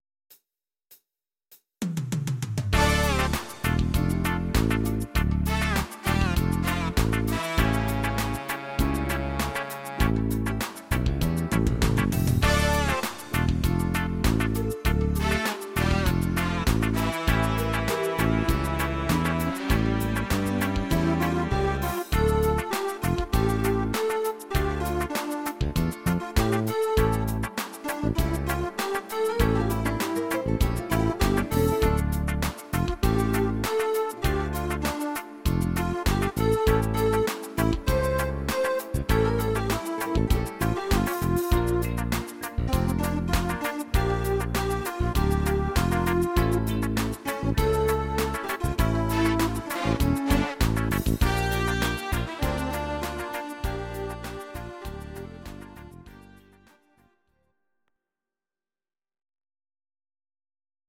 Audio Recordings based on Midi-files
Pop, Duets, 2000s